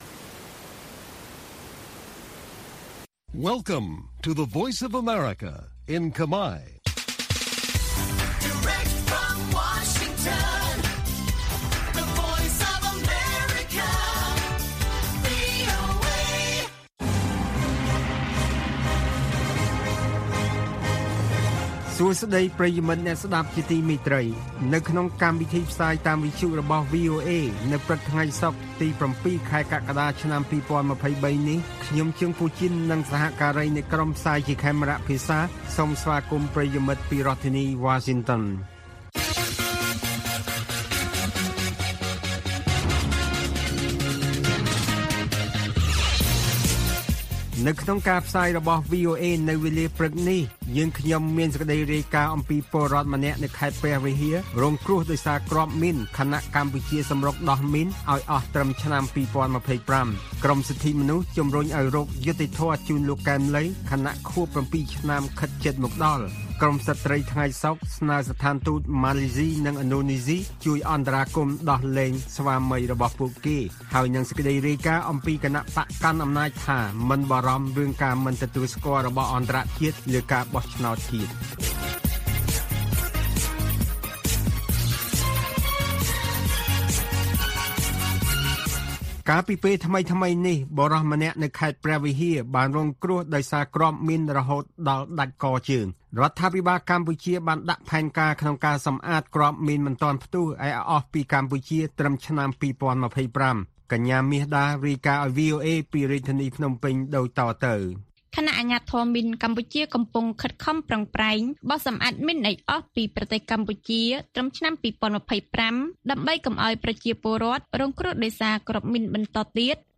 ព័ត៌មានពេលព្រឹក ៧ កក្កដា៖ ពលរដ្ឋម្នាក់នៅខេត្តព្រះវិហាររងគ្រោះដោយសារគ្រាប់មីន ខណៈកម្ពុជាសម្រុកដោះមីនឱ្យអស់ត្រឹមឆ្នាំ២០២៥